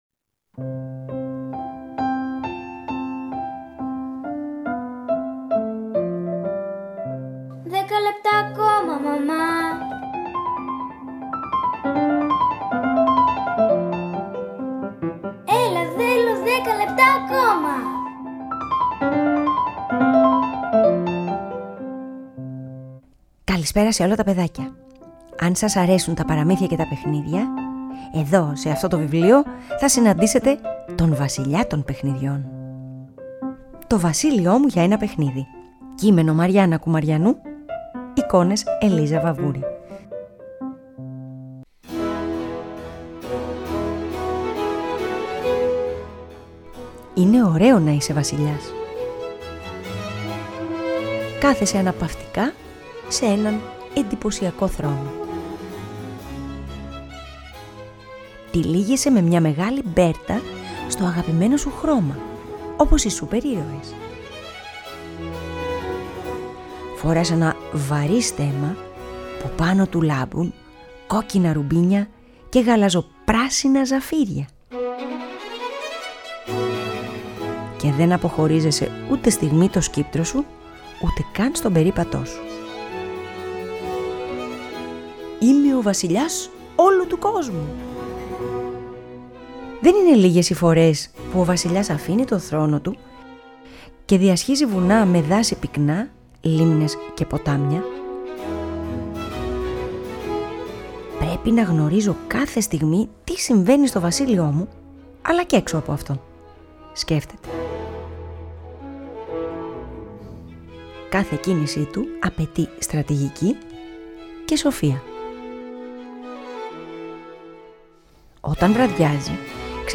Αφήγηση-Μουσικές επιλογές
ΠΑΡΑΜΥΘΙΑ